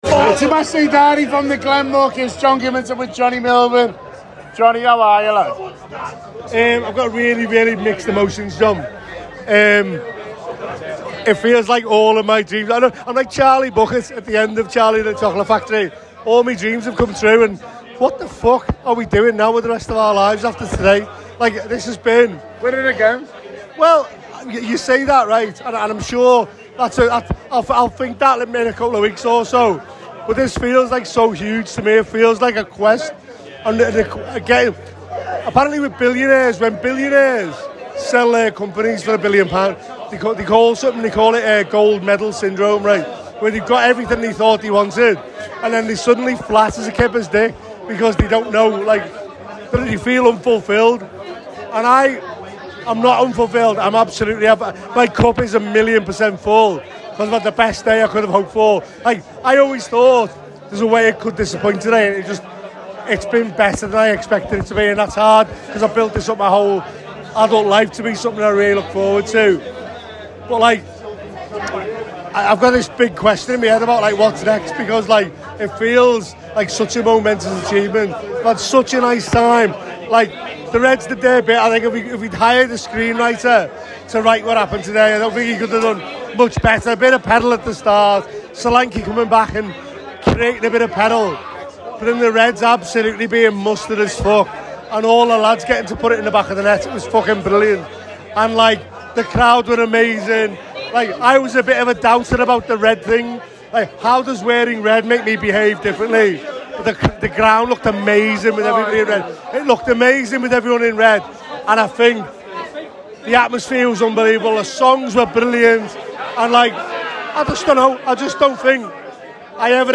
The Anfield Wrap’s Match Day Diary speaks to supporters at Anfield on the day Liverpool won the league.